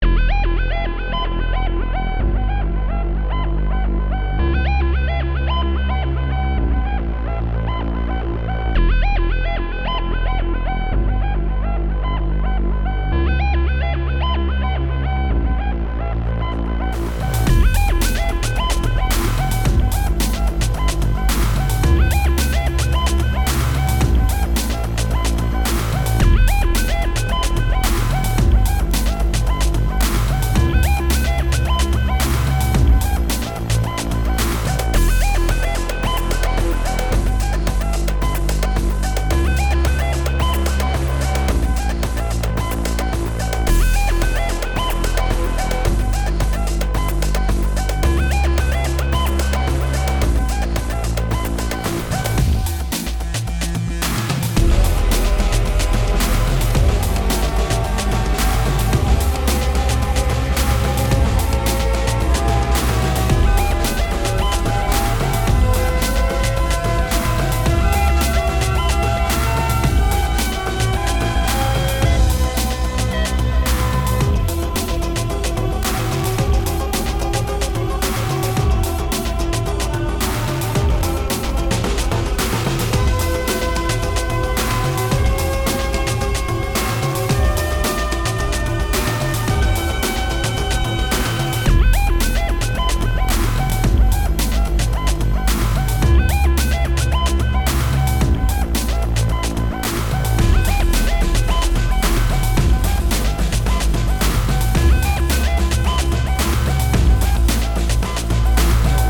action-track-4-ai-fight-jumping-cyborg-loopable.ogg